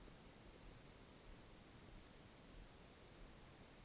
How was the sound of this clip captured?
R-09, iRiver HP120, and AT853 self noise comparisons Each is about 5 seconds from the quiet room. I did attempt to set them to the same gain using a 1khz test tone. HP120 AT853 9v batt.